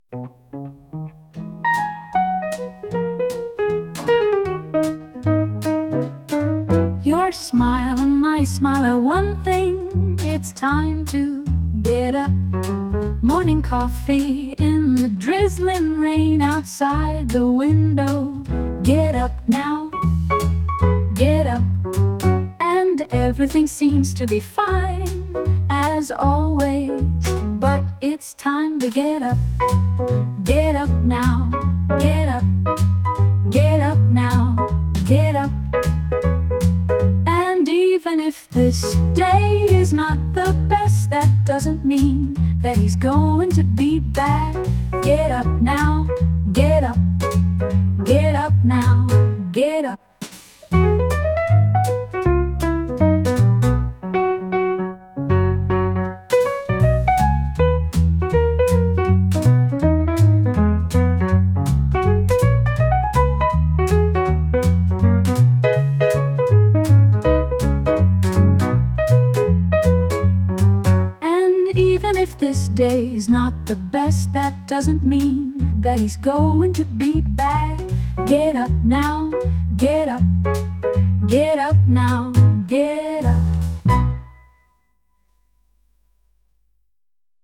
Morning-coffee-in-the-drizzling-rain-outside-the-window-3.mp3